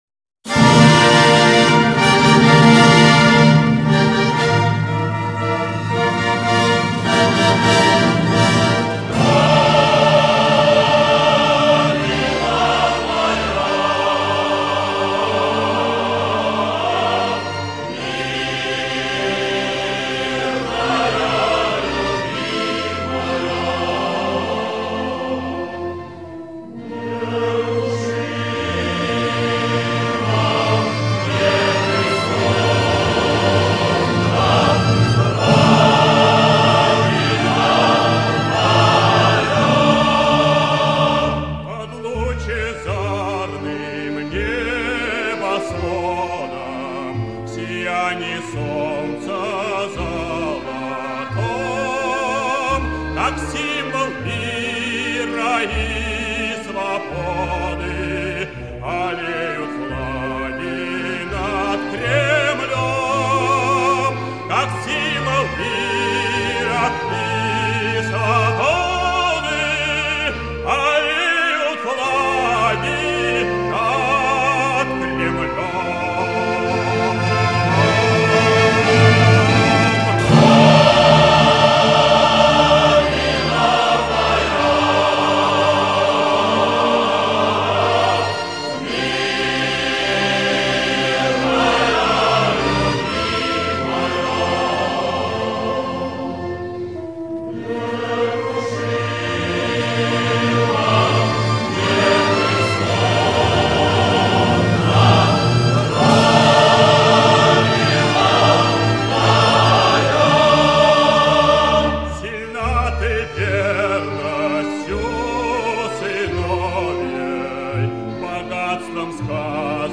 На смену по качеству куда-то пропавшей записи...